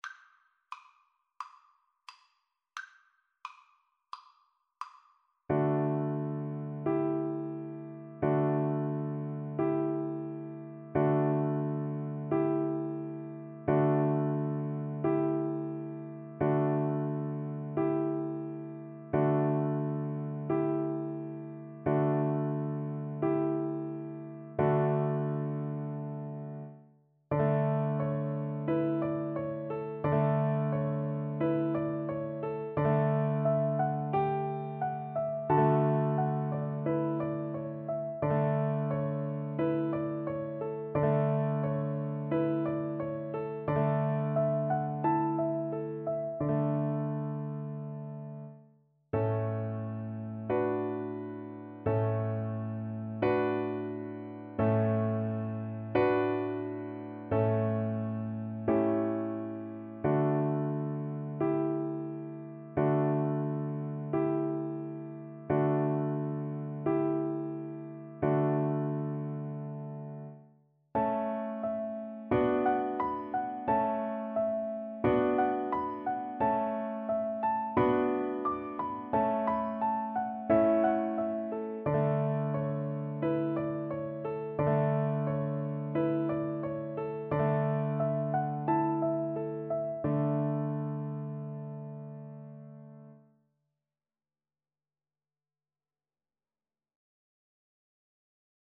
D major (Sounding Pitch) (View more D major Music for Viola )
Moderato = c. 88
Classical (View more Classical Viola Music)